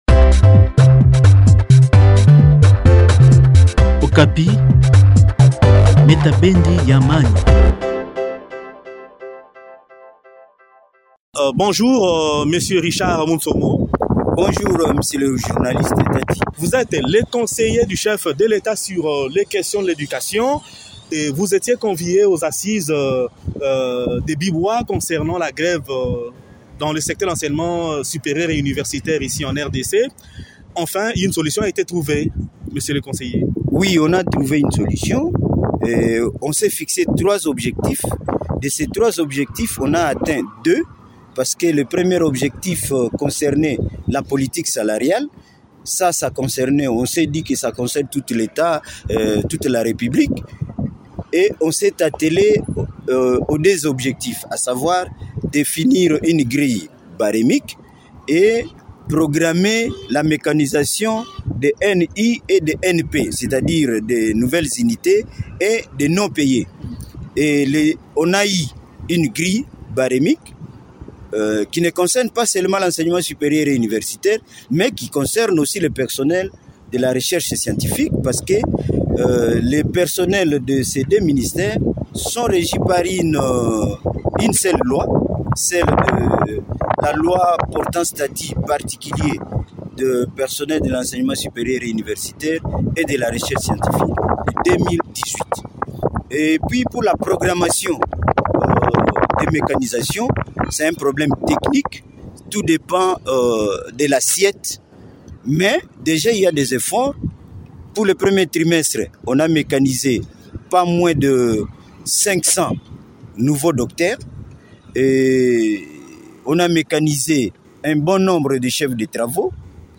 Le point sur ce sujet dans cet entretien